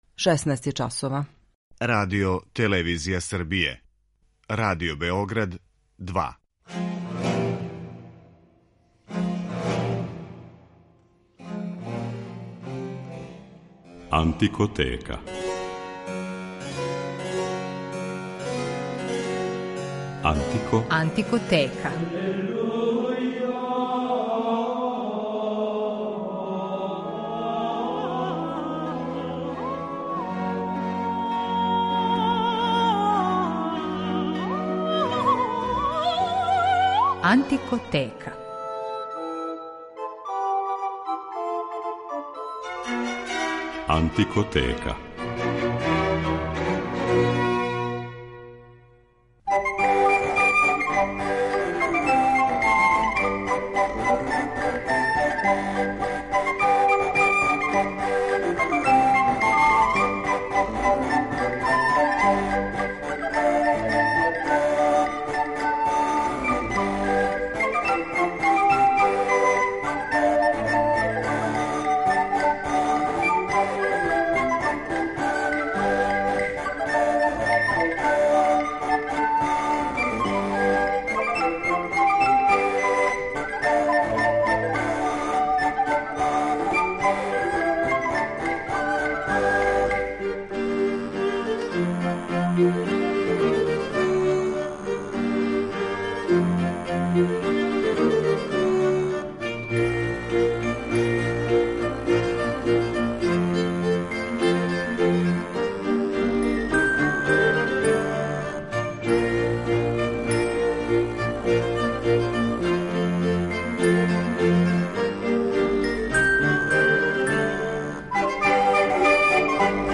Данашња емисија посвећена је веома популарном жанру у доба барока – музици за столом (tafelmusik).